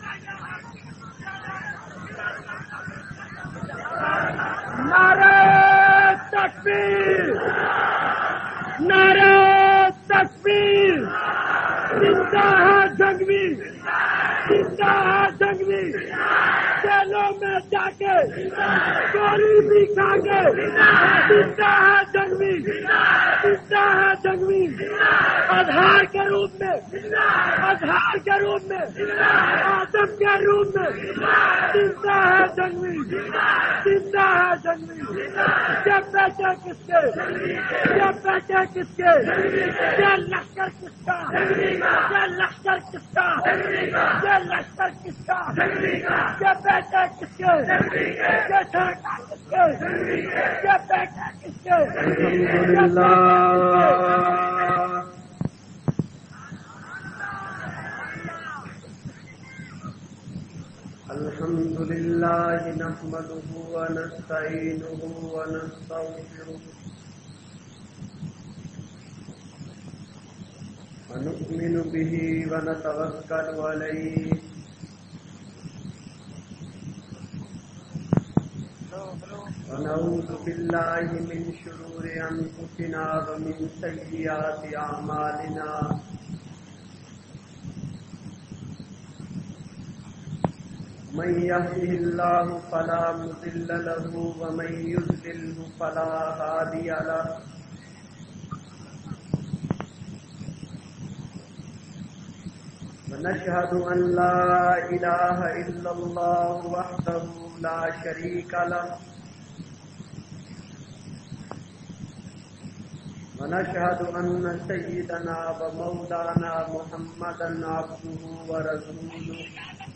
Shuhada e Islam Conference.mp3